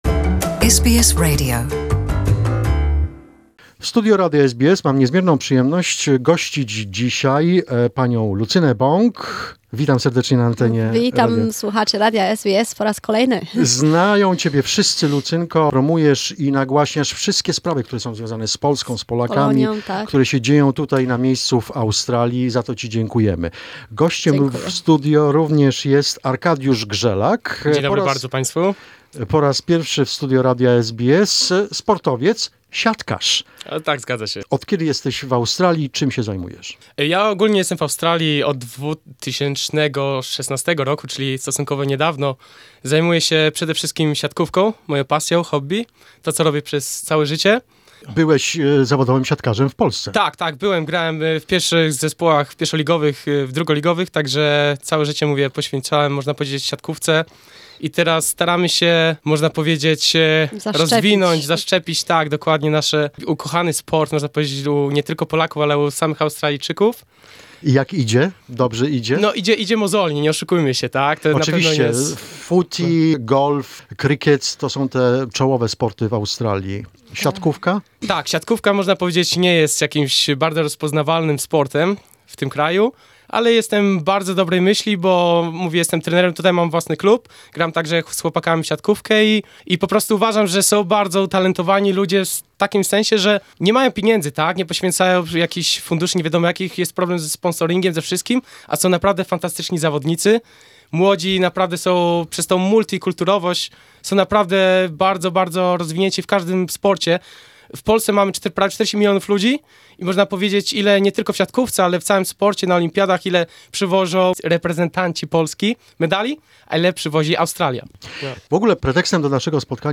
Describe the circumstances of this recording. visited Polish Program at SBS Radio in Melbourne to talk about the event...